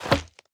Minecraft Version Minecraft Version snapshot Latest Release | Latest Snapshot snapshot / assets / minecraft / sounds / mob / turtle / armor.ogg Compare With Compare With Latest Release | Latest Snapshot